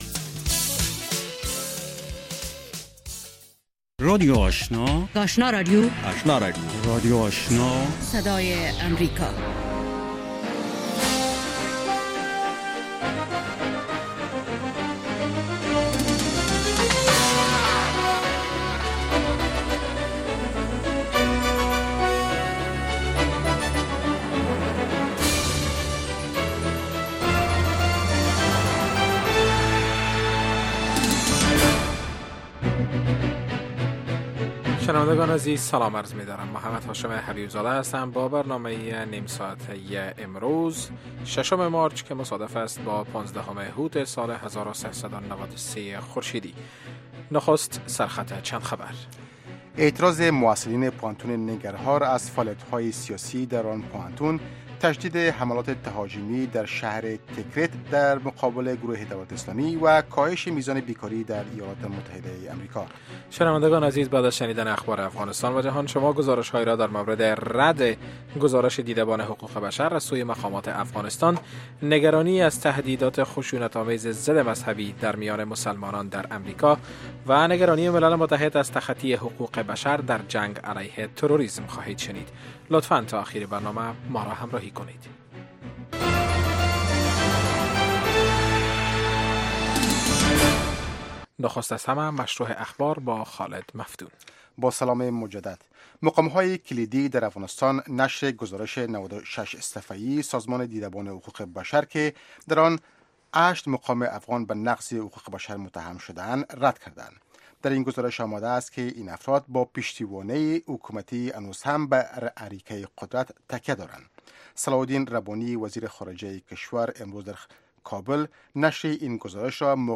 نخستین برنامه خبری شب